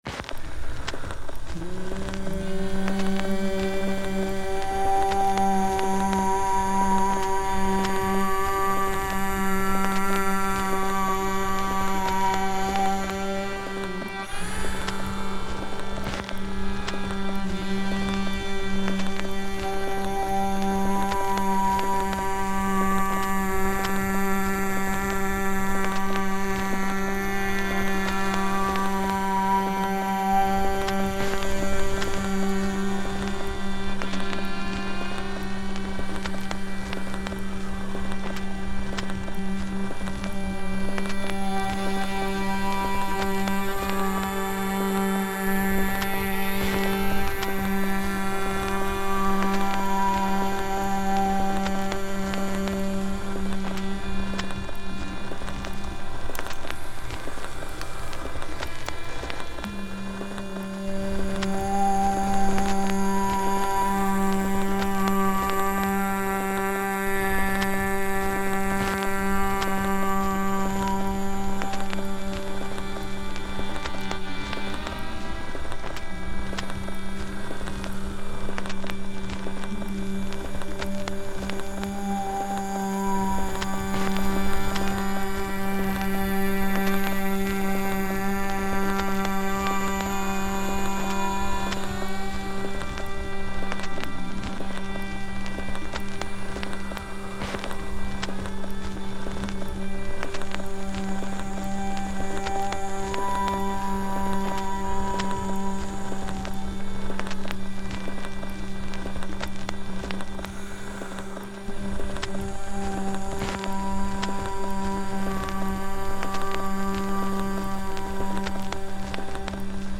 Soundscape
ambient soundtrack loop